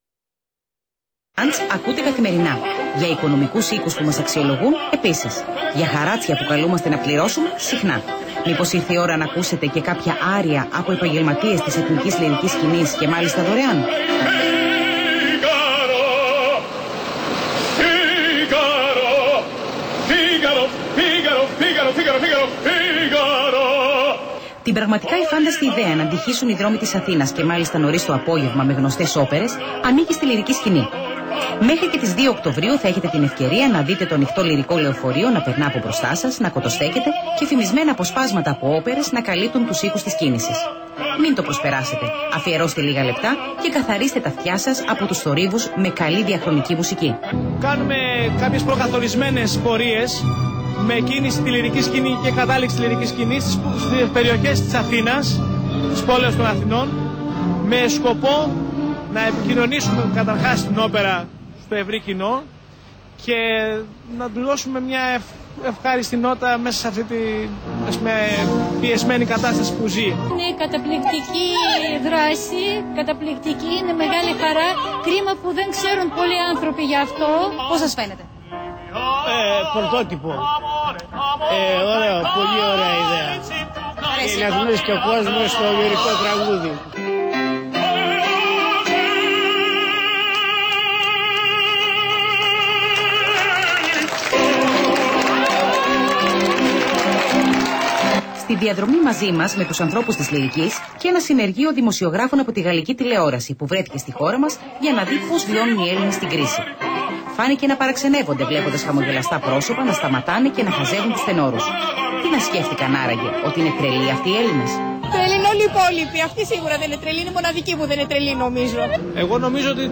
Επίπεδο: Β2 Δεξιότητα: Κατανόηση Προφορικού Λόγου